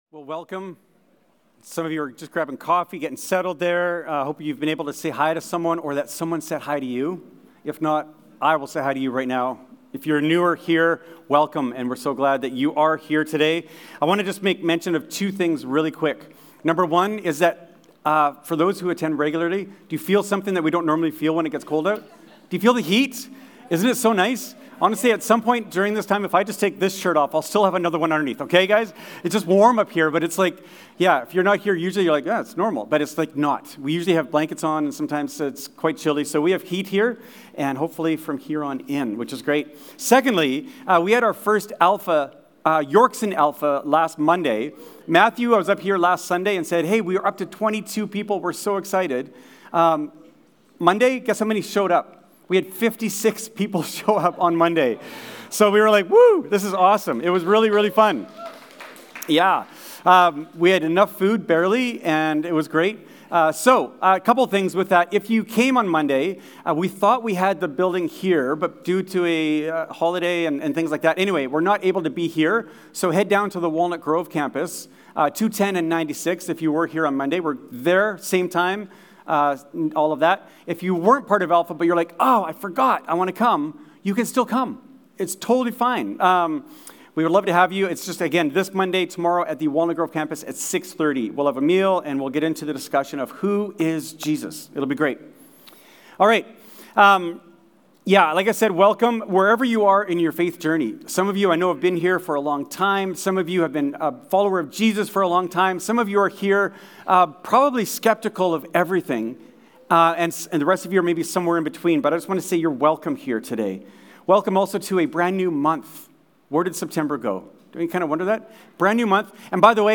Yorkson Sermons | North Langley Community Church